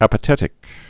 (ăpə-tĕtĭk)